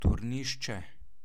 Turnišče (pronounced [ˈtuːɾniʃtʃɛ]
Sl-Turnisce.oga.mp3